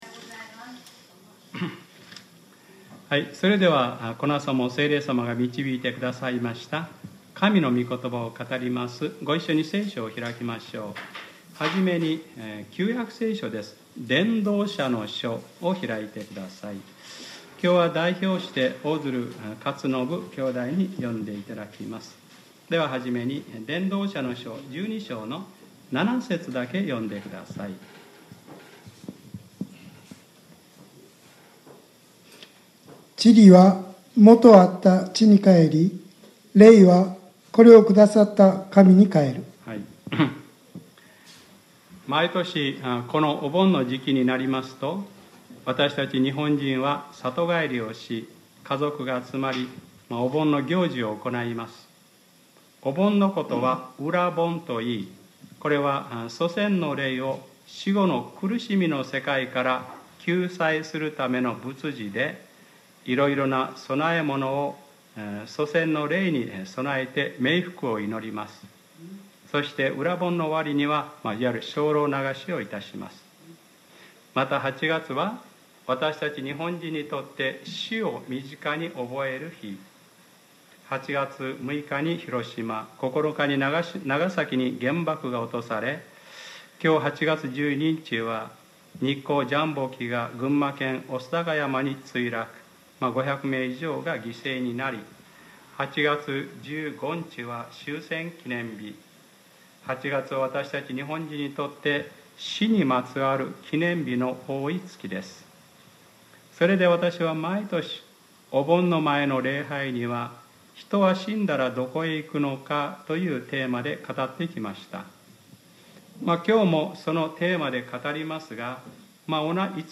2018年08月12日（日）礼拝説教『天国は本当にある』